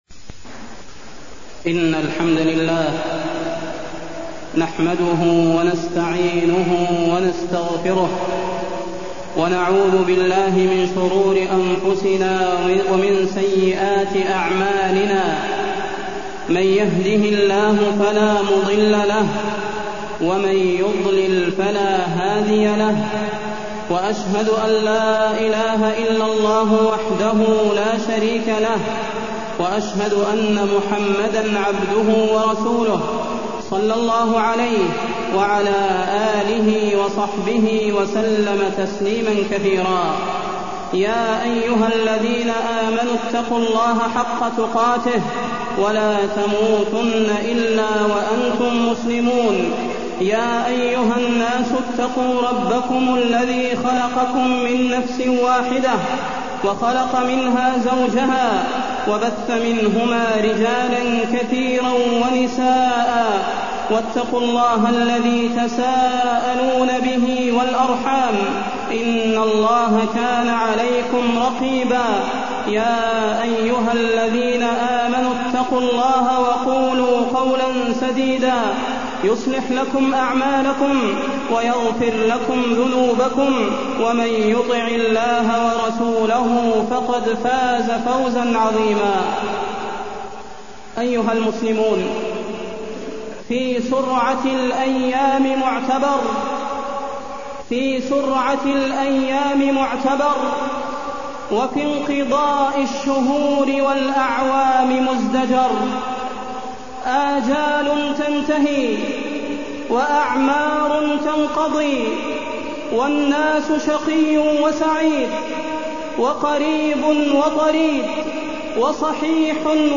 تاريخ النشر ٢٨ شعبان ١٤٢١ هـ المكان: المسجد النبوي الشيخ: فضيلة الشيخ د. صلاح بن محمد البدير فضيلة الشيخ د. صلاح بن محمد البدير استقبال شهر رمضان